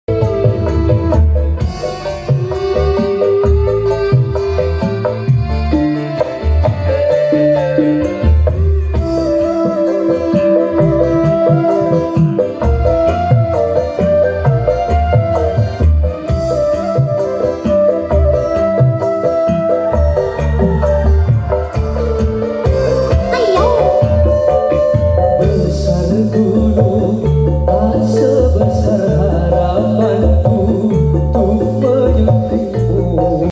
MH112 Mode Briket 3in1 Dangdut Sound Effects Free Download